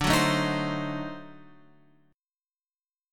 D 7th Flat 9th